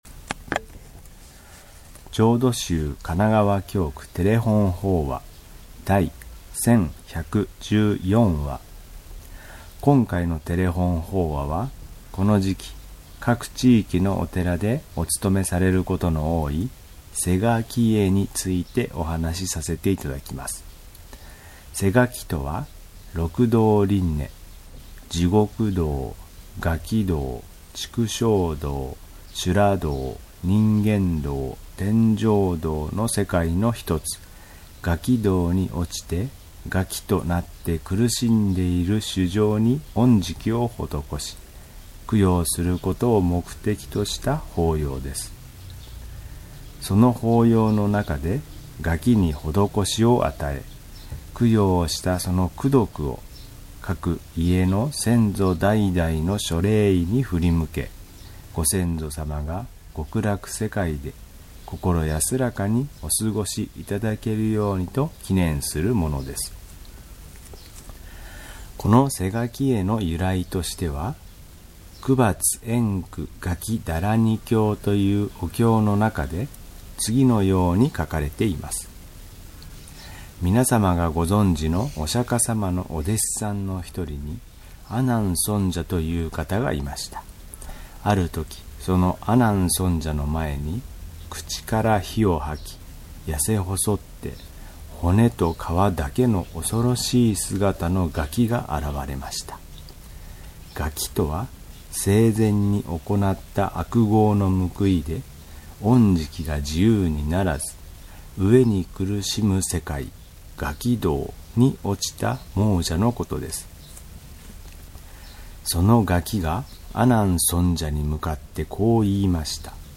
テレホン法話
法話